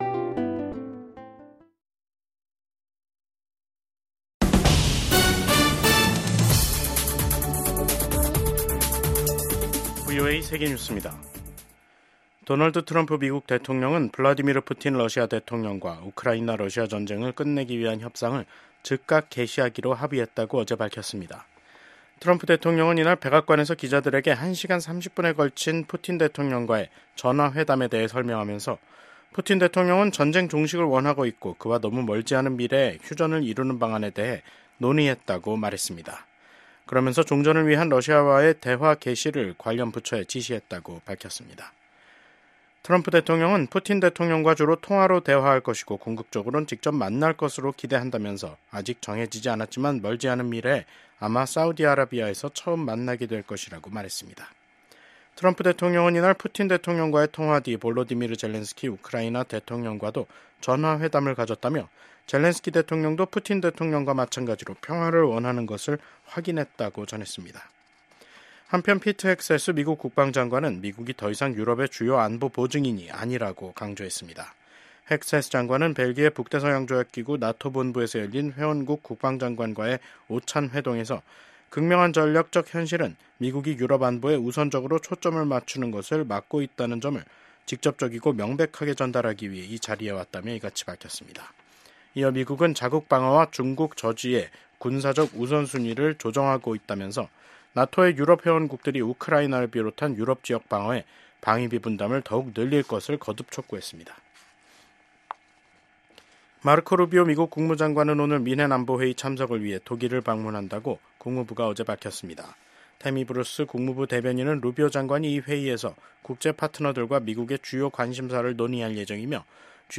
VOA 한국어 간판 뉴스 프로그램 '뉴스 투데이', 2025년 2월 13일 2부 방송입니다. 북한이 남북 화해의 상징인 금강산 관광지구 내 이산가족면회소를 철거 중인 것으로 파악됐습니다. 미국 군함을 동맹국에서 건조하는 것을 허용하는 내용의 법안이 미국 상원에서 발의됐습니다. 미국 경제 전문가들은 트럼프 대통령의 철강∙알루미늄 관세 부과는 시작에 불과하다면서 앞으로 더 많은 관세가 부과돼 미한 경제 관계에 긴장이 흐를 것으로 전망했습니다.